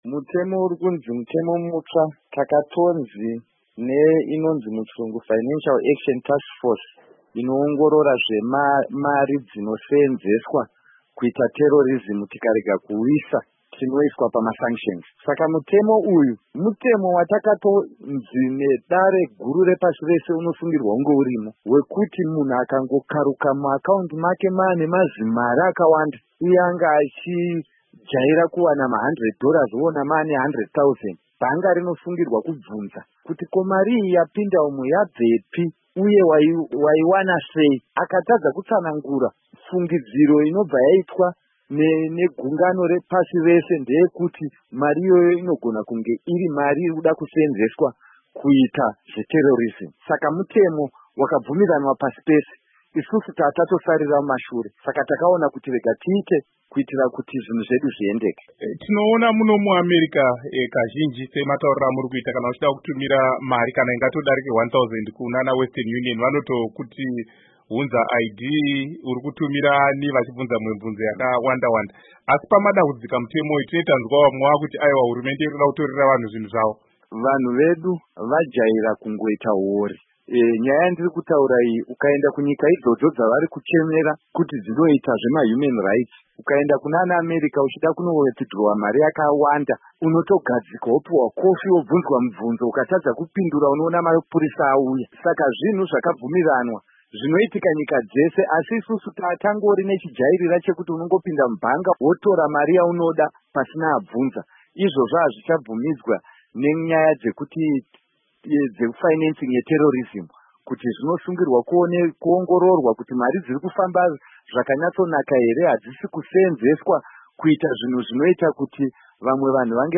Hurukuro naVaZiyambi Ziyambi